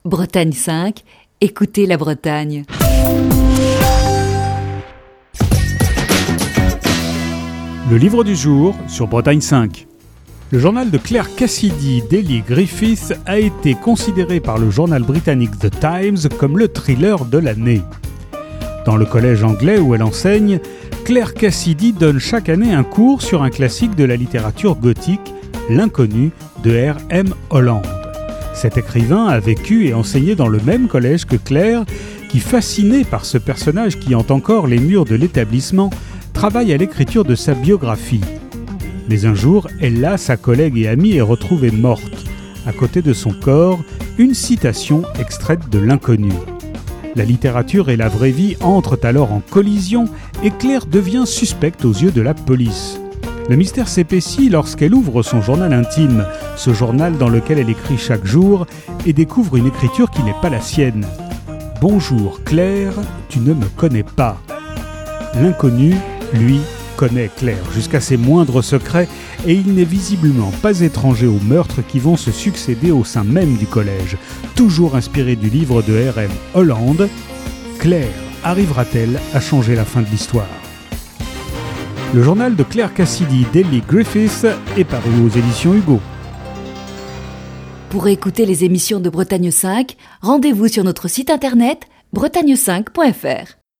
Chronique du 10 mars 2020.